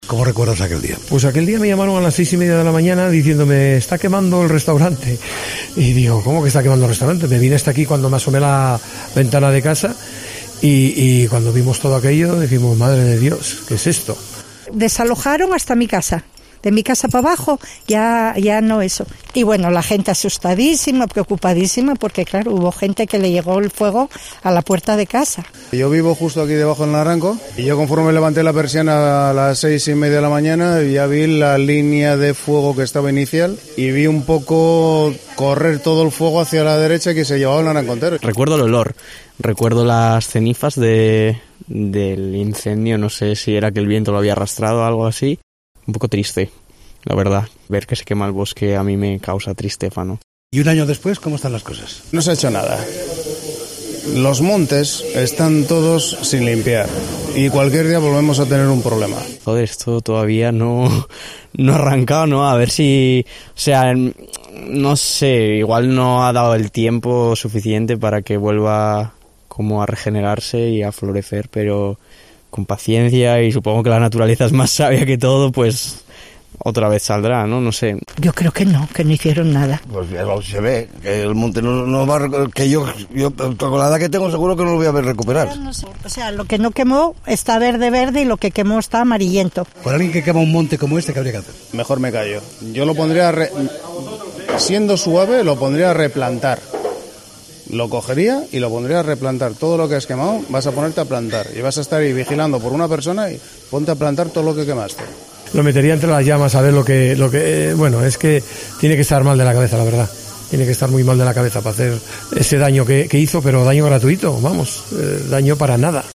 Recordamos con los vecinos del Naranco el incendio que estuvo a punto de quemar sus casas